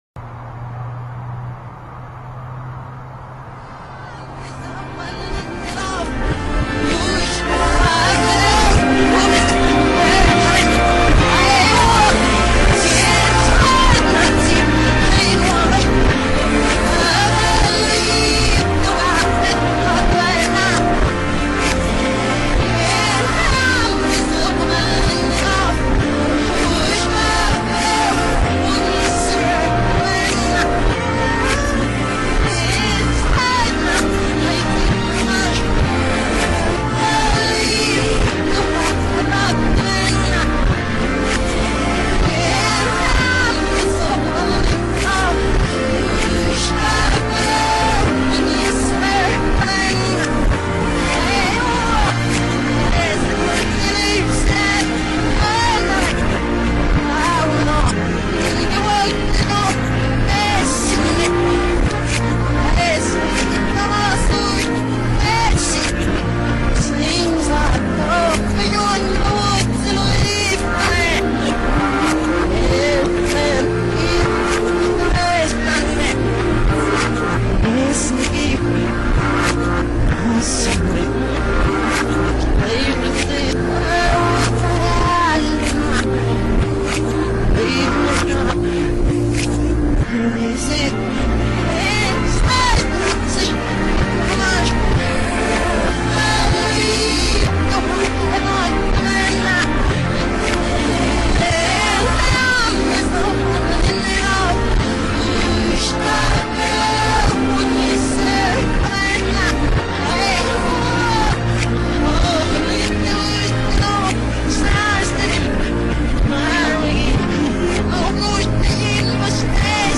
played in REVERSE / BACKMASKED